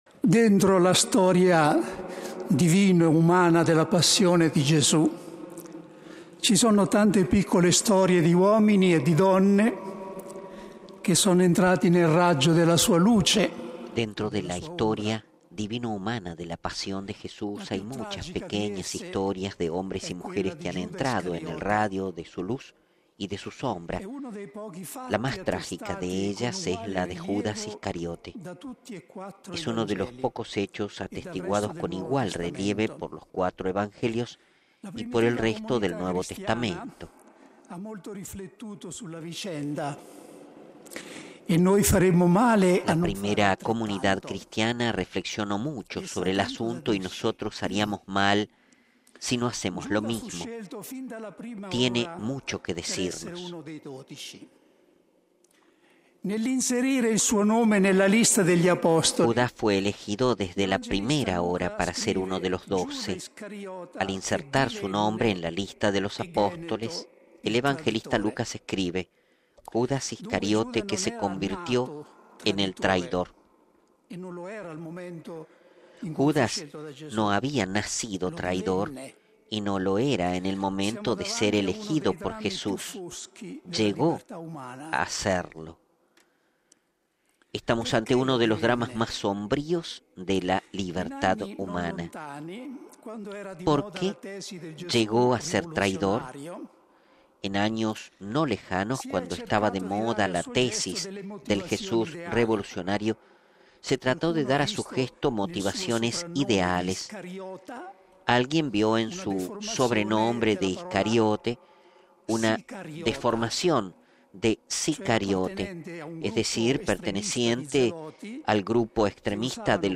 (RV).- (audio) (se actualizó con video) El Papa Francisco preside la celebración de la Pasión del Señor en la basílica Vaticana, la tarde del Viernes Santo. Las meditaciones de este año están a cargo del Padre Rainiero Cantalamessa, predicador de la Casa Pontificia.